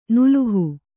♪ nuluhu